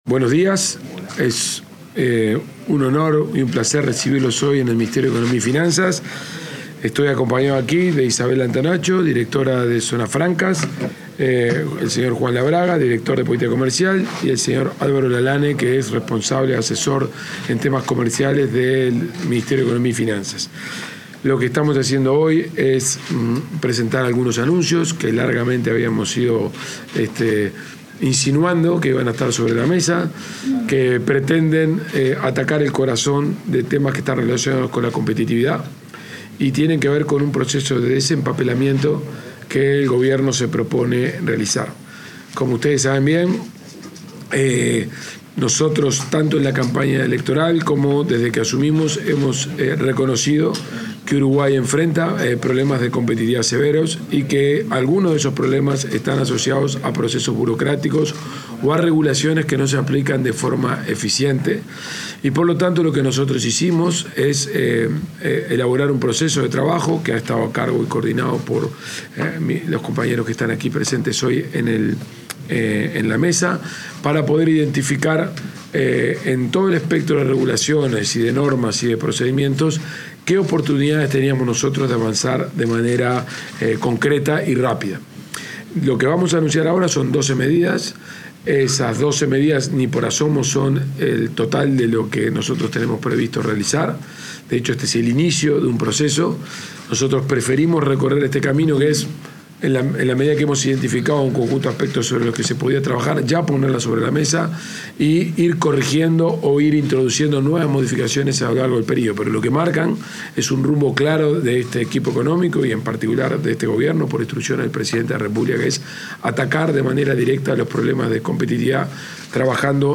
Palabras del ministro de Economía y el equipo técnico del MEF
MEF_conferencia.mp3